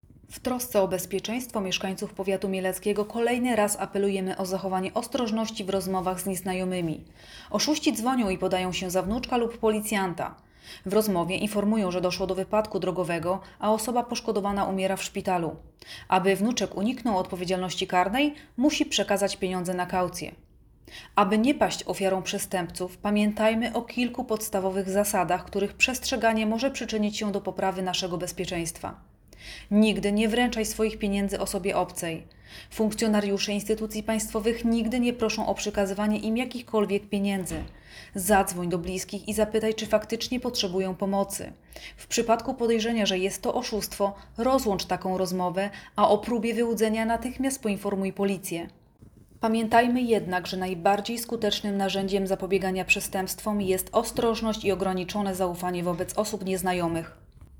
Nagranie audio Zachowaj ostrożność! Nie daj się oszukać!, mówi